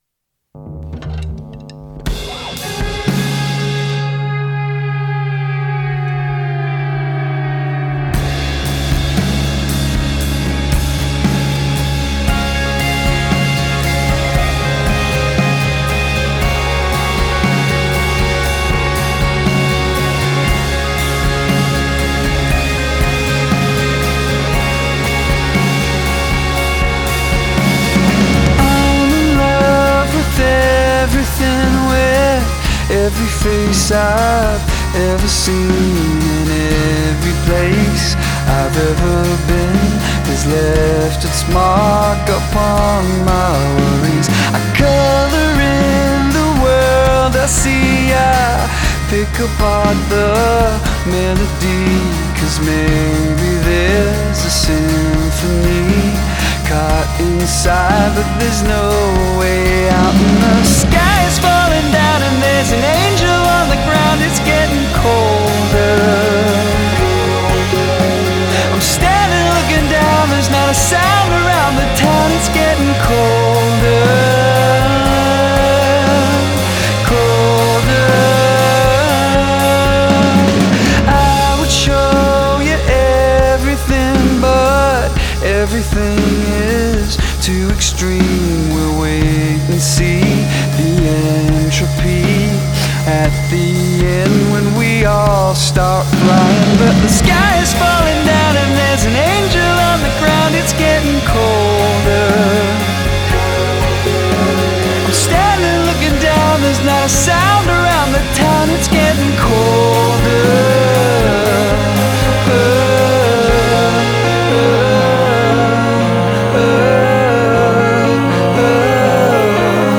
Indie Indie pop Alternative rock